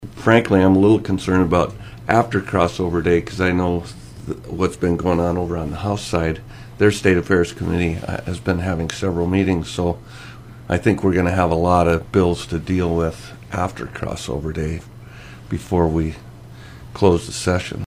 Senator Jim Mehlhaff chairs Senate State Affairs and has been cracking the whip with extra meetings…